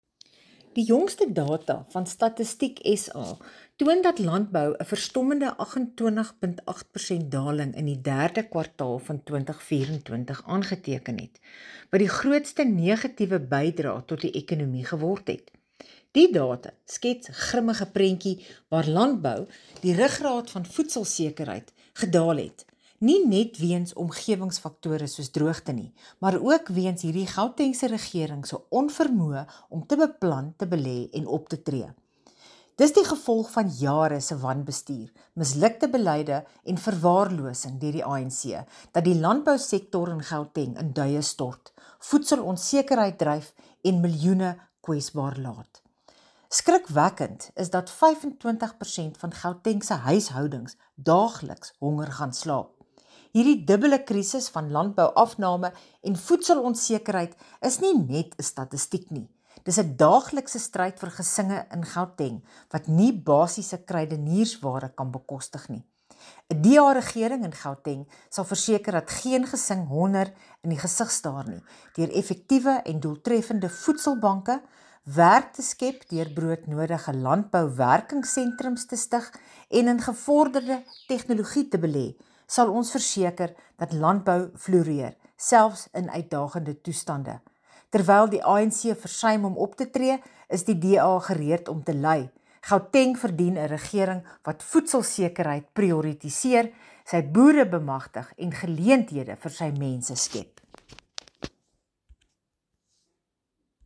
Issued by Bronwynn Engelbrecht MPL – DA Gauteng Shadow MEC for Agriculture and Rural Development
Afrikaans by DA MPL, Bronwynn Engelbrecht.